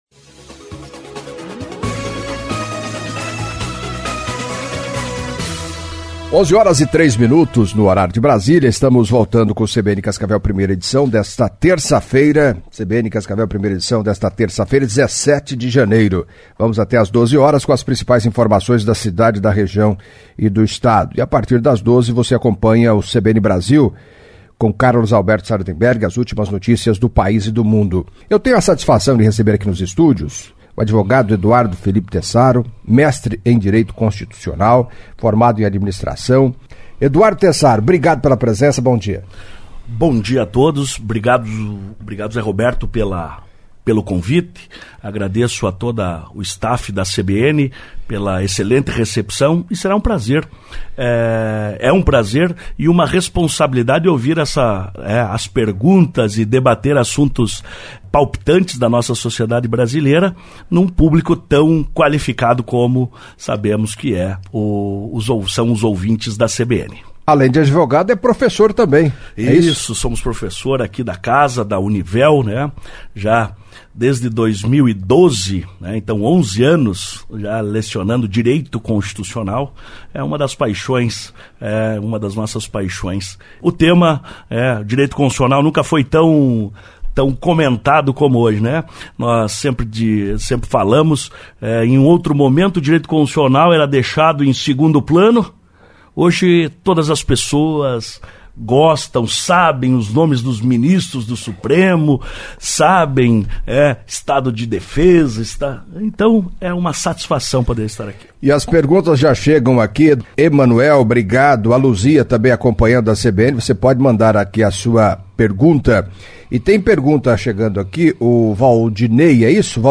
Em entrevista à CBN Cascavel nesta terça-feira (17)
além de responder dúvidas de ouvintes